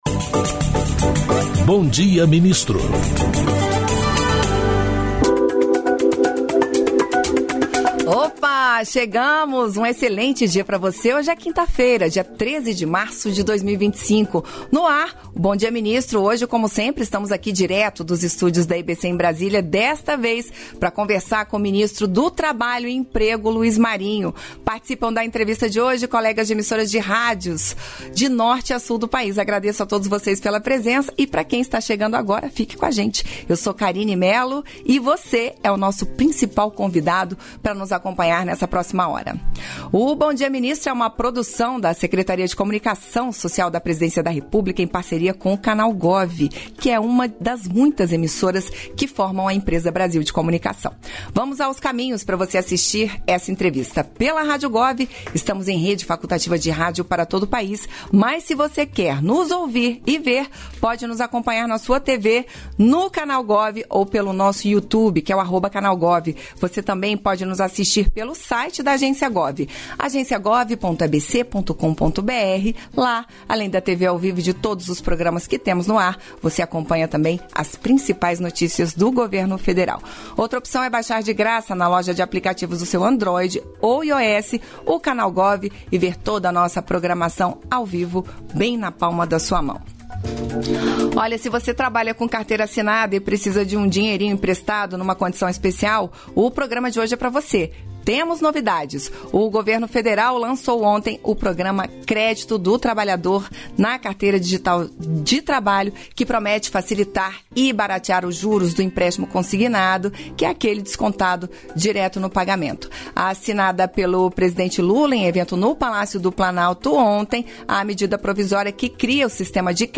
Íntegra da participação do ministro do Trabalho e Emprego, Luiz Marinho, no programa "Bom Dia, Ministro" desta quinta-feira (13), nos estúdios da EBC, em Brasília.